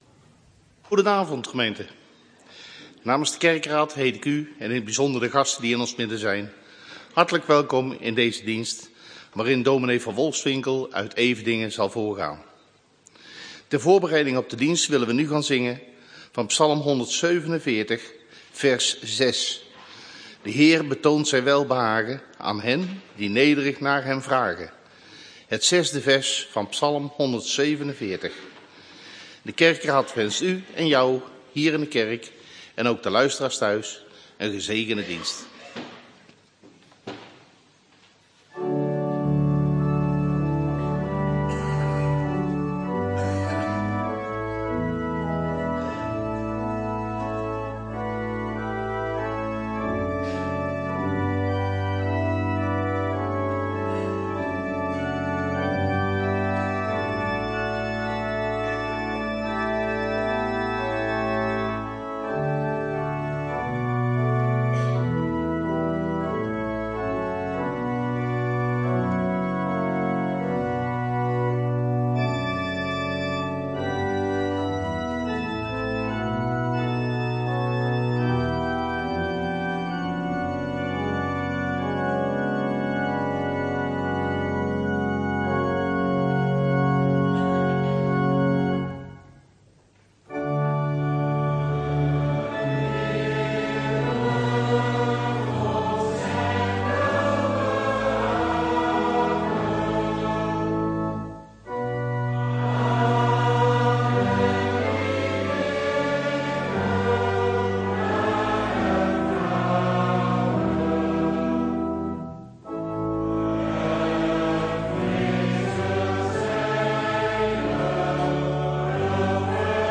Opnamen Kerkdiensten